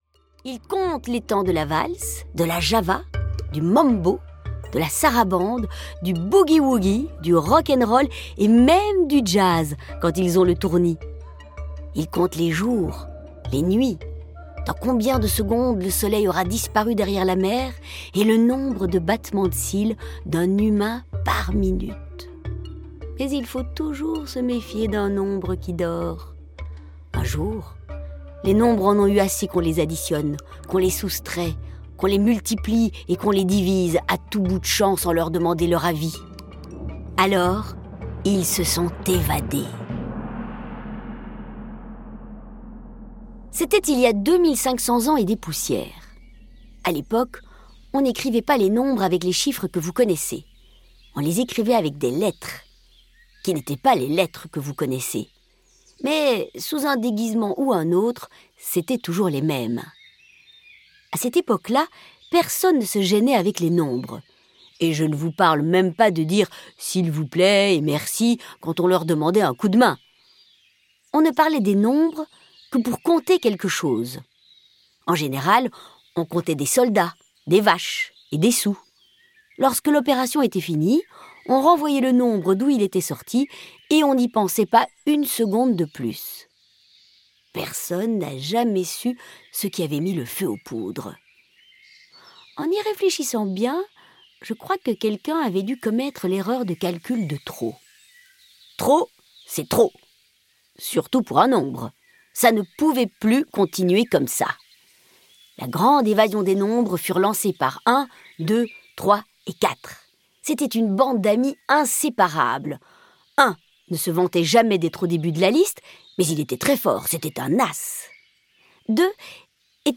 Click for an excerpt - Pythagore et la grande évasion des nombres de Louise Guillemot, Maureen Dor